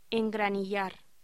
Locución: Engranillar